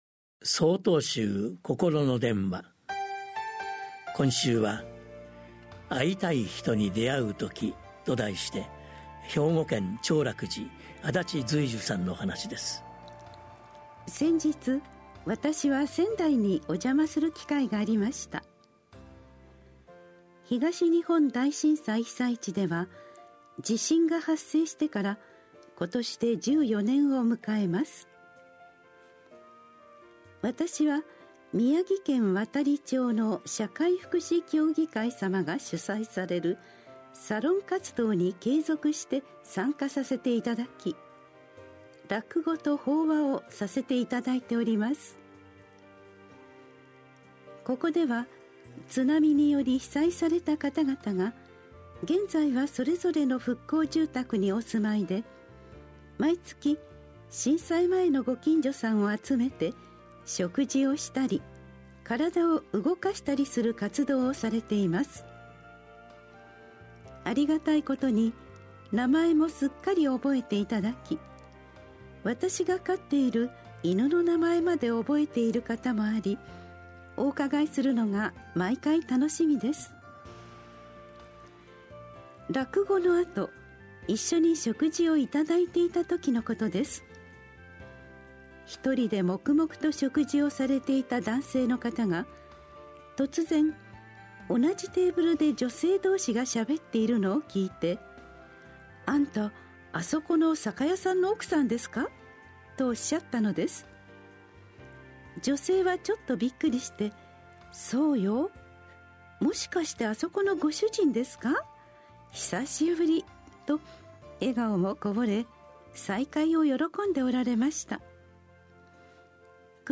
曹洞宗がお届けするポッドキャスト配信法話。 禅の教えを踏まえた「ほとけの心」に関するお話です。